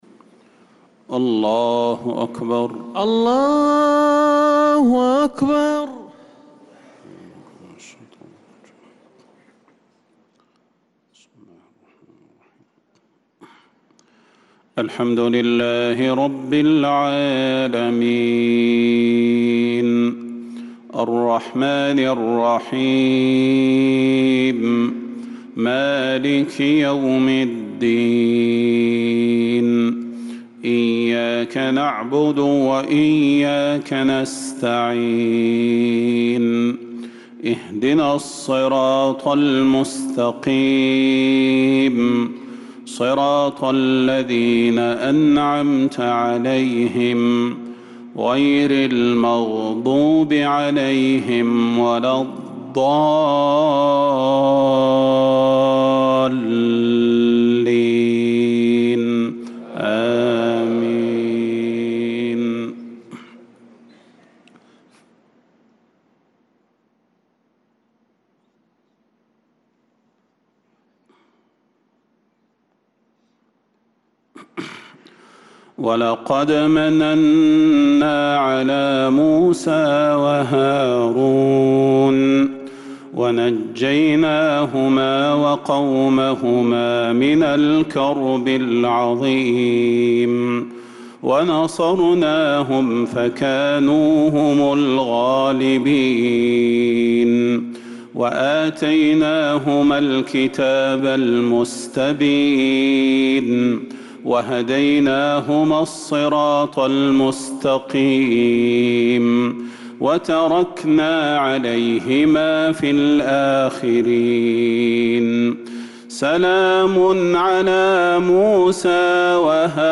صلاة العشاء للقارئ صلاح البدير 4 جمادي الأول 1446 هـ
تِلَاوَات الْحَرَمَيْن .